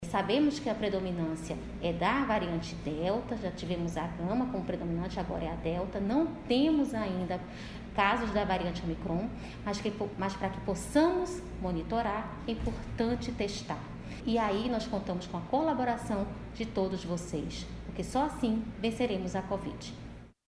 Os exames estão disponíveis gratuitamente para os passageiros que desembarcam em um dos três locais de acesso a cidade de Manaus. Como explica a diretora- presidente da FVS-RCP, Tatyana Amorim.
Sonora-2-_Tatyana-Amorim_diretora-presidente-da-FVS.mp3